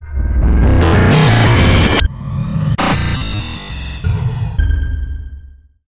Звуки глитч-эффектов
Нарастающий результат nnНарастающее влияние nnНарастающее воздействие nnЭффект нарастания